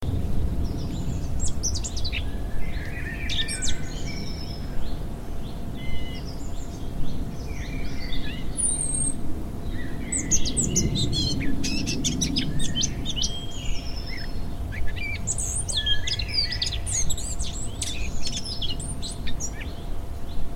Category: Animals/Nature   Right: Personal
Tags: scary